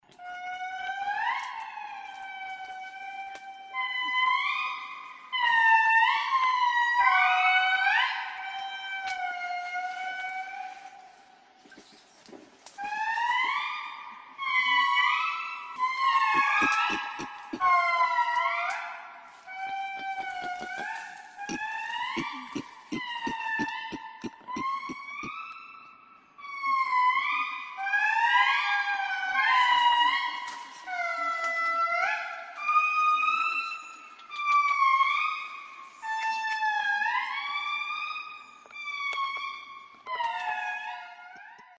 Звуки лемуров
В лесной чаще